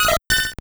Cri de Togepi dans Pokémon Or et Argent.